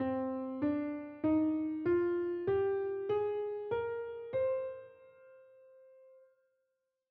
Figure 5.1 C natural minor scale.
C-Minor-Scale-S1.wav